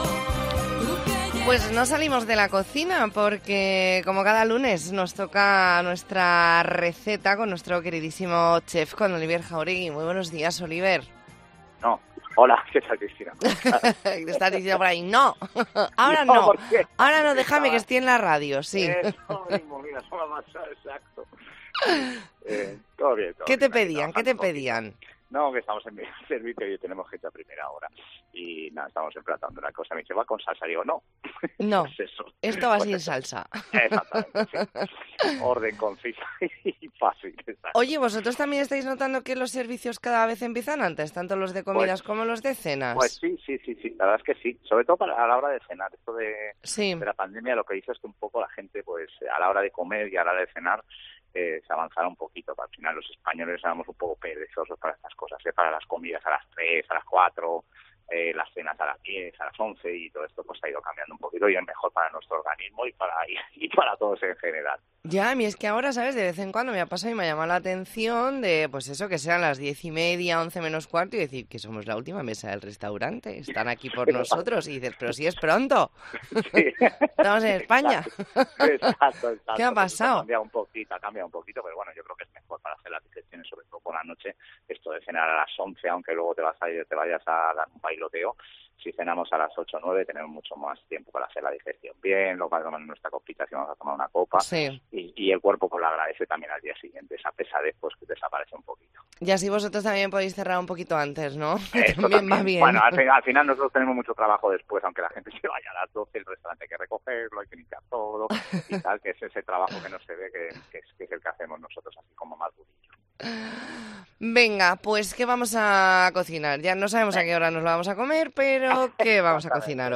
Entrevista en La Mañana en COPE Más Mallorca, lunes 4 de diciembre de 2023.